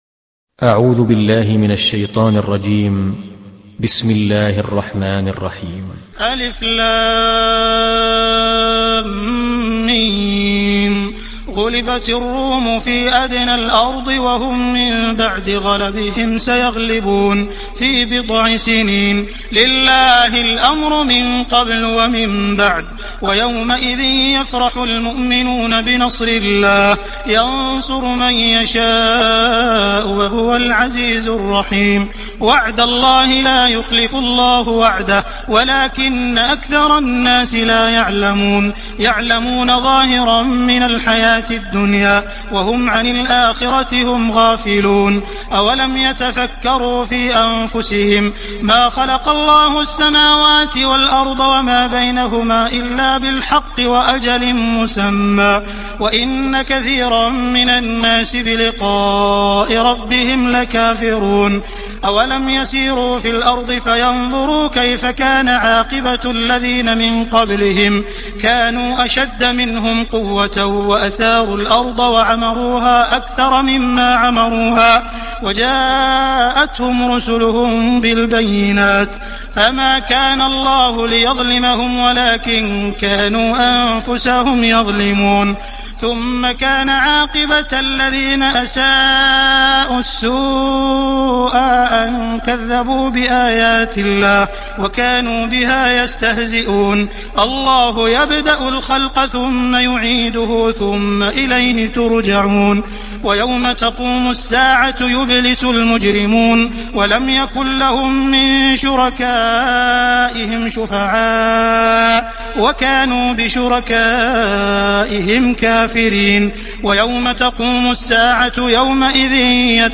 Surah Ar Rum mp3 Download Abdul Rahman Al Sudais (Riwayat Hafs)